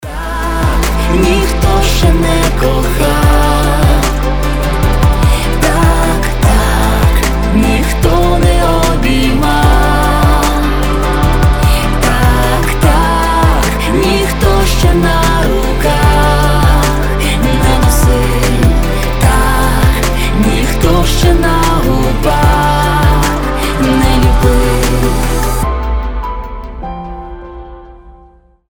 • Качество: 320, Stereo
красивые
дуэт